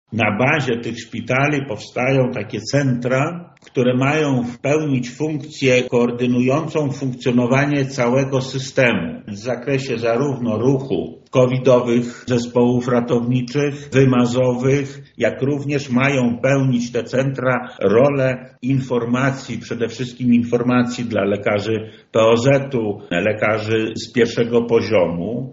-mówi Wojewoda Lubelski Lech Sprawka.